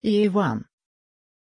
Pronunciación de Ieuan
pronunciation-ieuan-sv.mp3